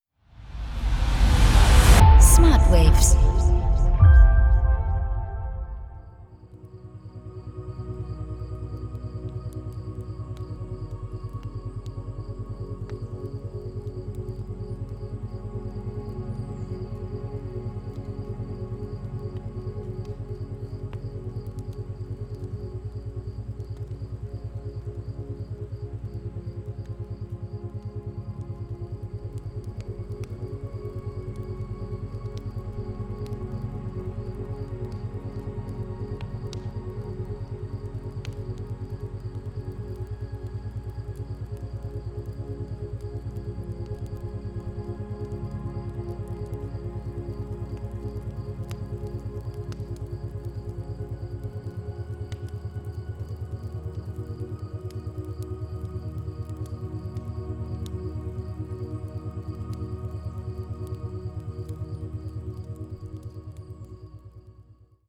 Isochrone Beats